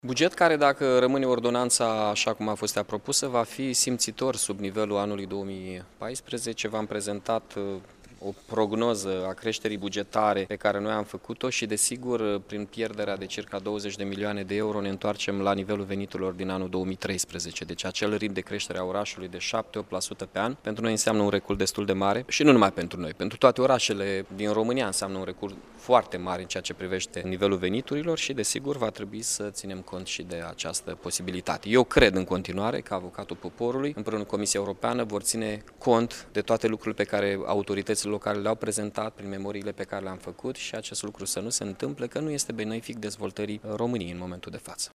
Pierderile bugetare vor fi de aproximativ 20 de milioane de euro, a mai spus Mihai Chirica:
27-sec-rdj-17-Chirica-modificari-cod-fiscal.mp3